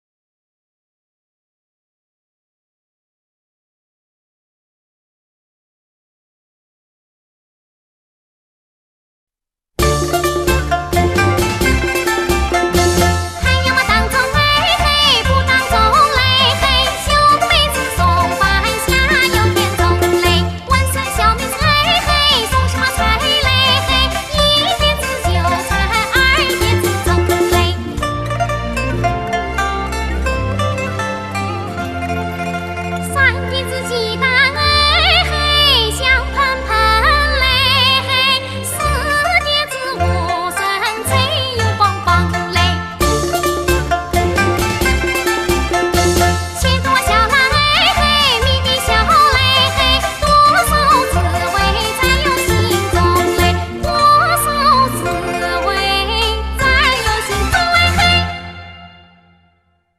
当前播放 小妹子送饭下田冲（当涂民歌）-001
民歌